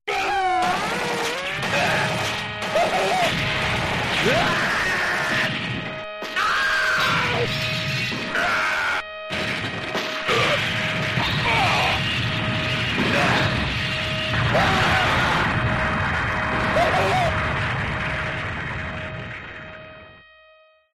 sounds of war